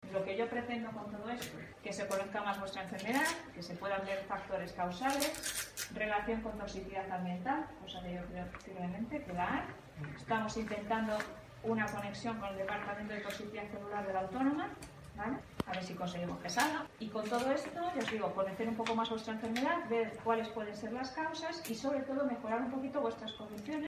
como comentó formato MP3 audio(0,18 MB) -con motivo de la presentación de ambos estudios- a un grupo de afectados.